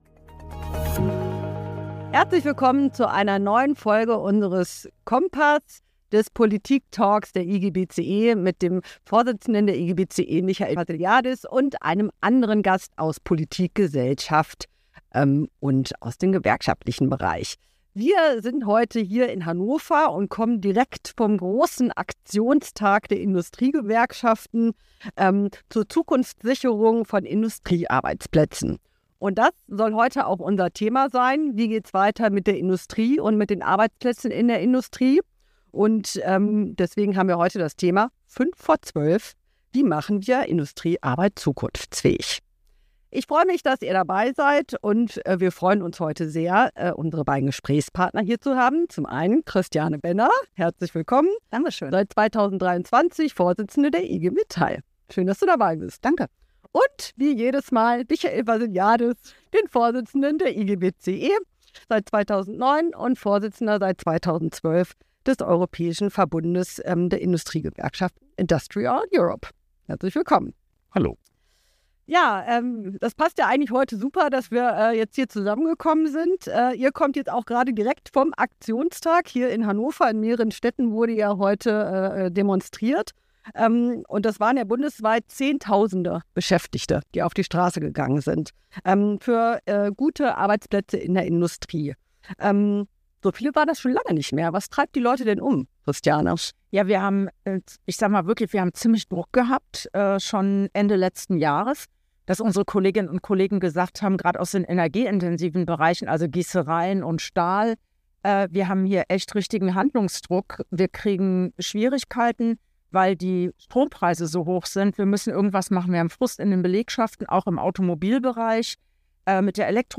Der Talk zum Aktionstag: Die Gewerkschaftsvorsitzenden Christiane Benner und Michael Vassiliadis diskutieren direkt nach der Demo im neuen „IGBCE Kompass“ über die Zukunft der Industrie und ihrer Arbeitsplätze.